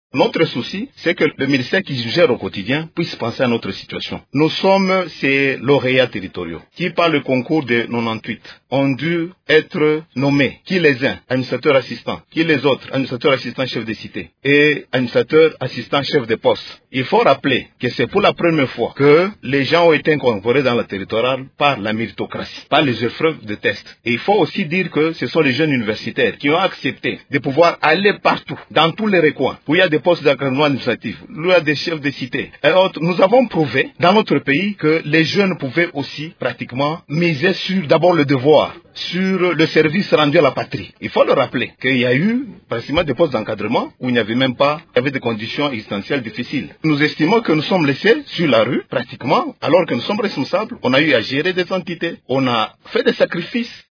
Dans cet extrait sonore, il estime qu'ils sont «les seuls sur la rue» alors qu'ils ont eu à gérer des entités territoriales: